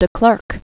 (də klûrk, klĕrk), F(rederik) W(illem) Born 1936.